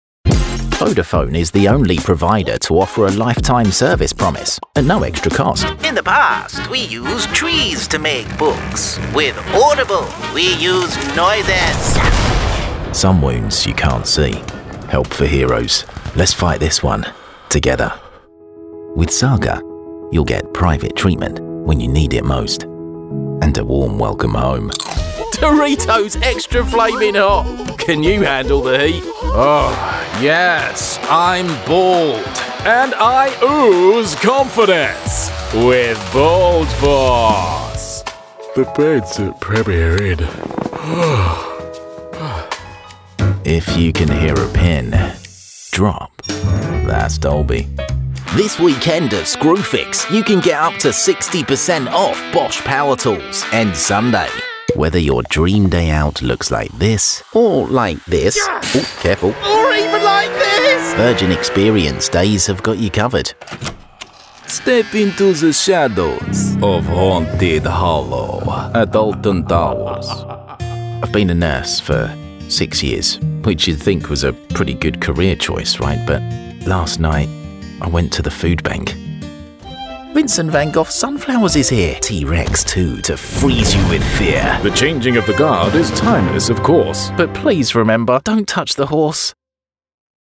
Inglés (Reino Unido)
Demo comercial
Mi voz es muy similar a la del lugar donde vivo, es cercana, accesible y realista, pero me siento igual de cómodo exagerando que manteniéndome tranquilo.
Tengo un estudio de nivel profesional con una cabina insonorizada.
BarítonoProfundoBajo